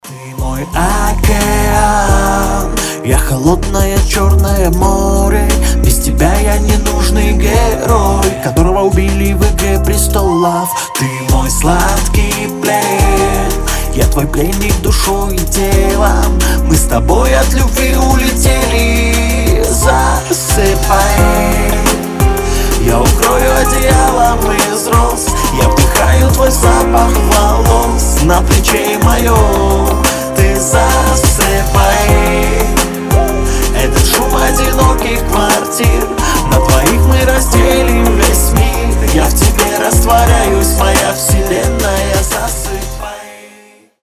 Хип-хоп
мелодичные
романтичные
колыбельные
лиричные